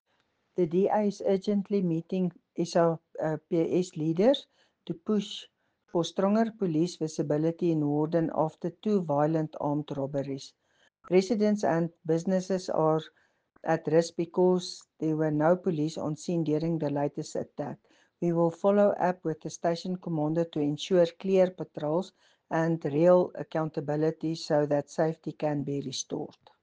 English and Afrikaans soundbites by Cllr Doreen Wessels and